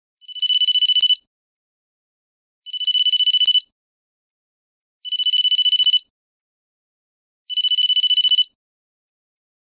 business-cell-phone_24813.mp3